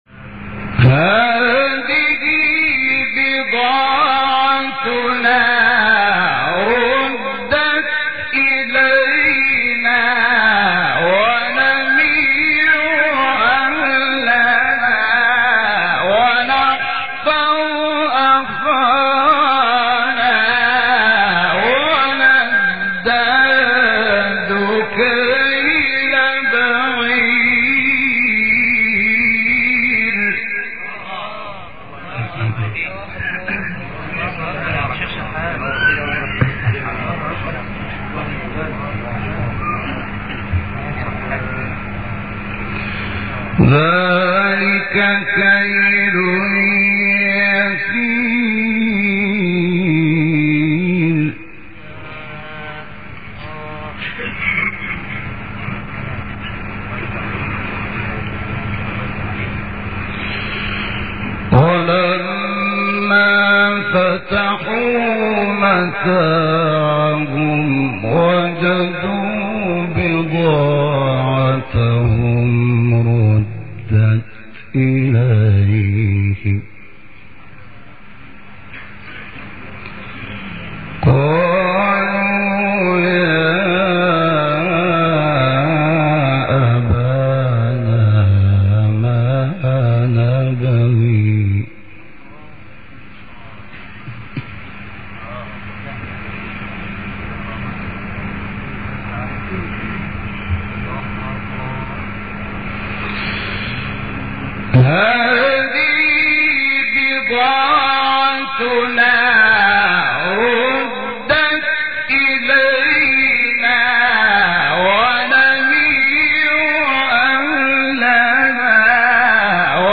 سوره : یوسف آیه: 65 استاد : شحات محمد انور مقام : بیات قبلی بعدی